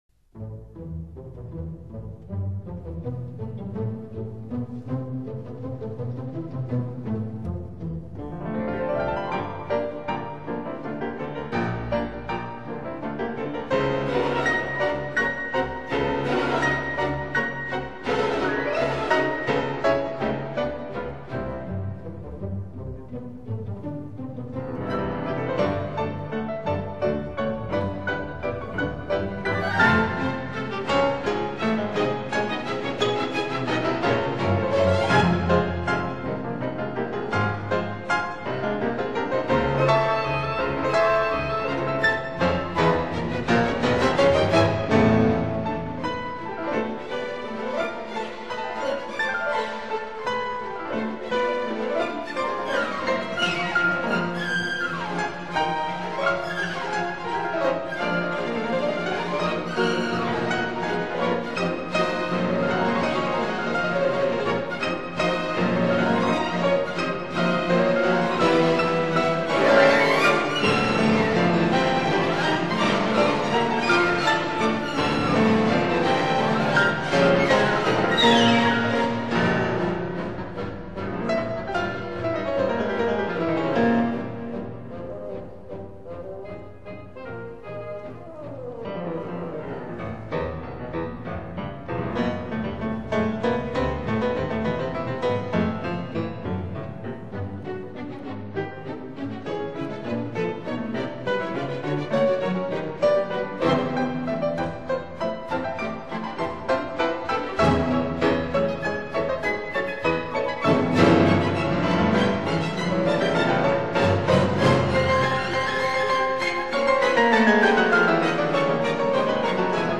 Allegro ma non troppo